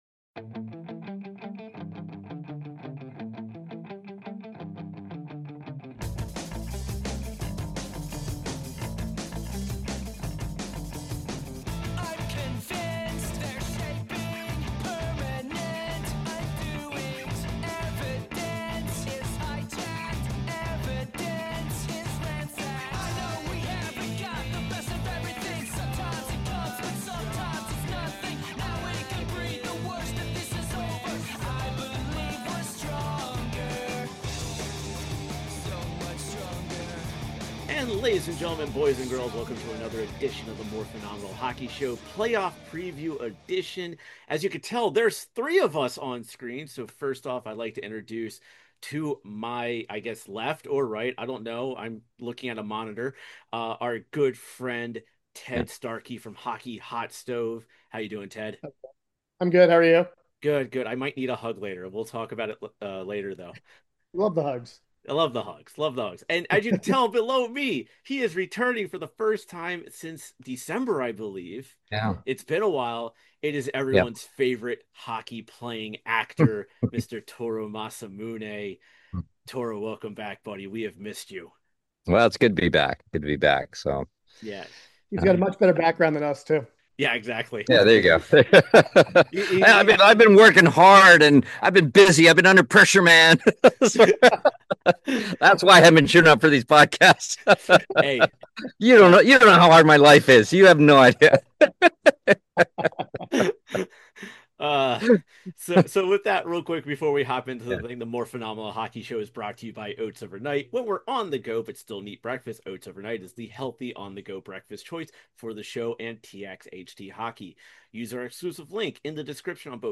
Ladies and Gentlemen welcome to The Morphinominal Hockey Show for some fun hockey conversations with actors from the Power Rangers and TMNT franchises
Opening Music